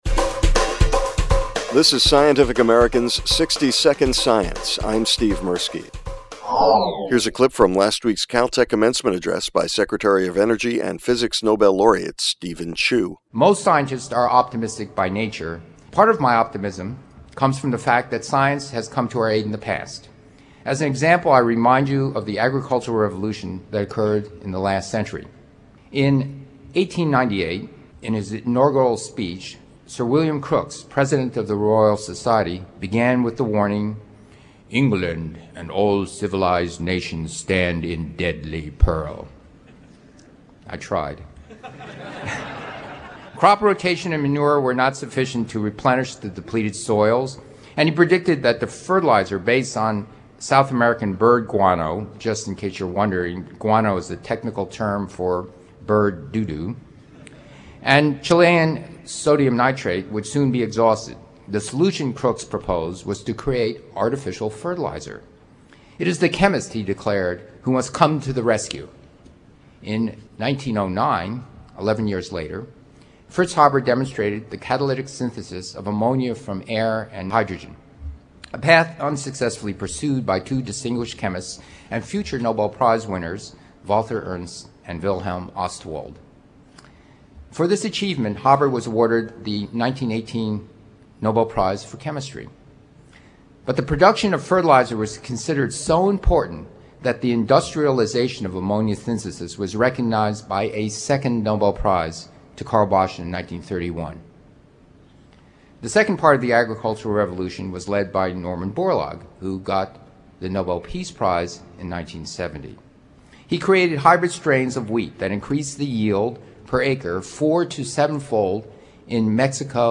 Here’s a clip from last week’s CalTech commencement address by Secretary of Energy and physics Nobel Laureate Steven Chu: